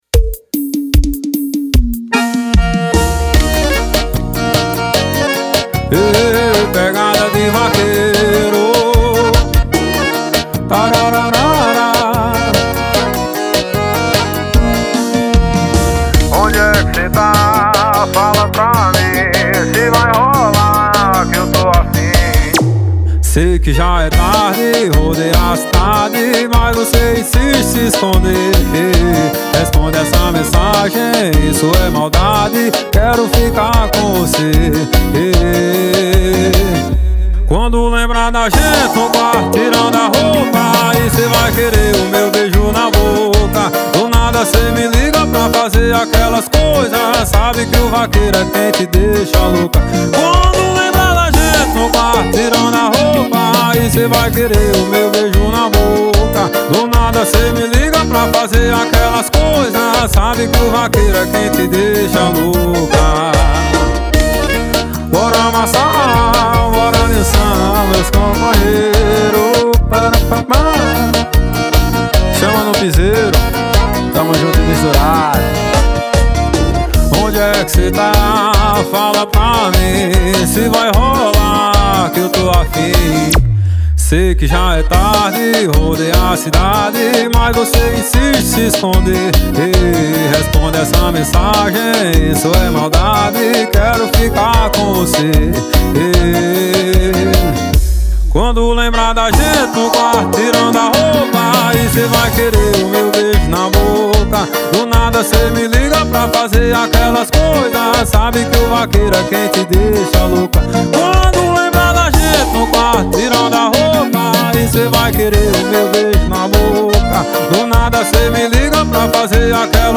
2024-02-14 17:56:19 Gênero: Forró Views